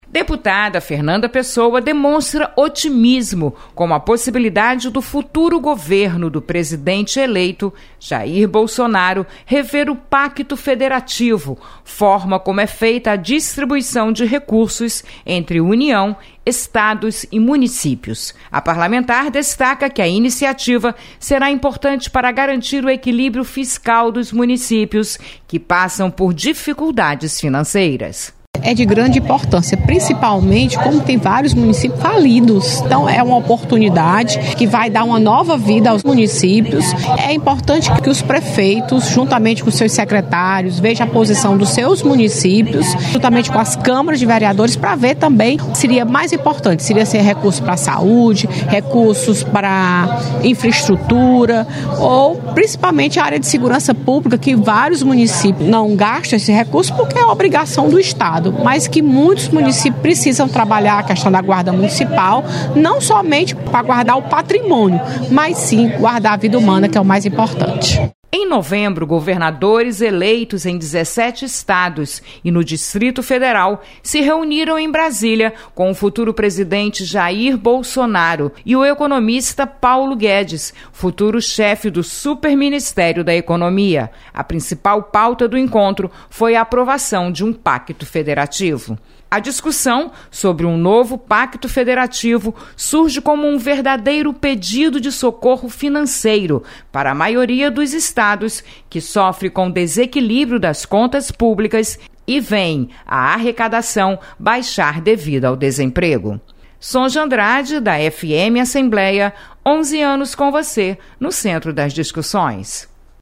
Deputada Fernanda Pessoa demonstra otimismo com a  suposta revisão do Pacto Federativo. Repórter